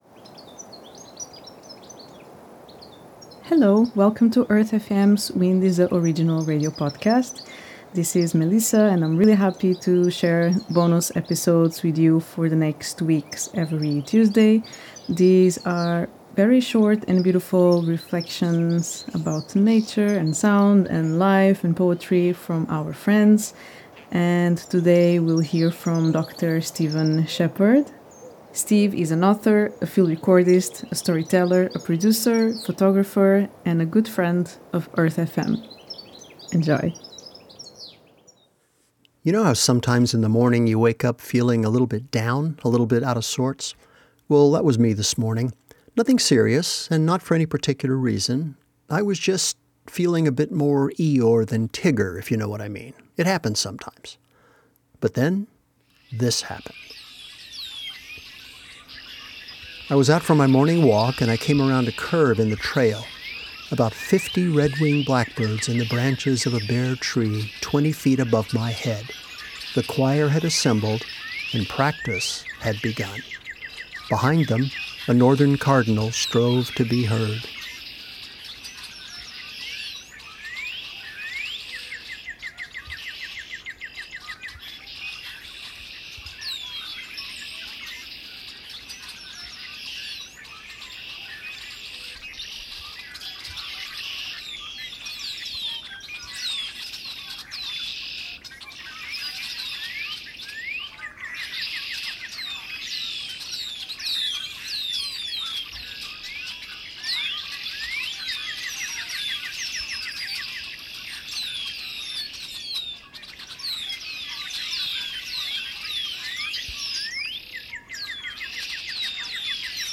Wind Is the Original Radio Morning Blackbirds